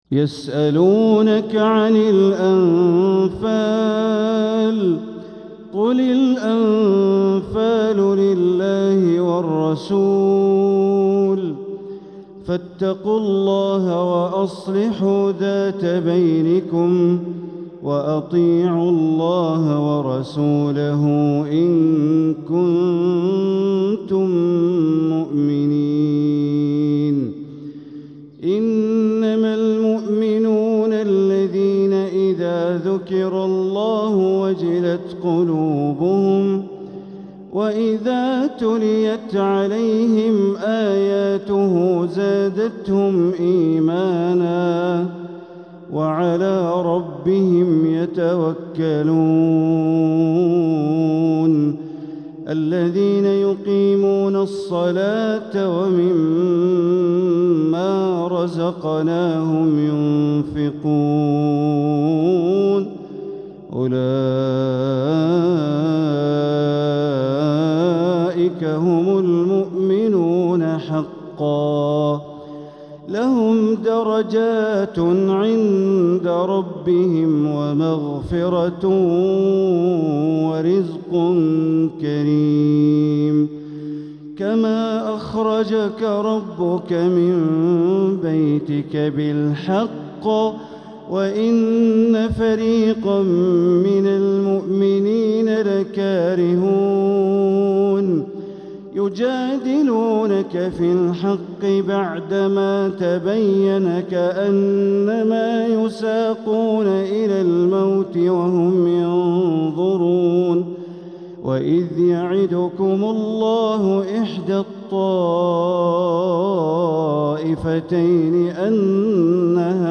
تلاوة لفواتح سورة الأنفال ١-١٤ | فجر الأحد ٩ جمادى الأخرة ١٤٤٧ > 1447هـ > الفروض - تلاوات بندر بليلة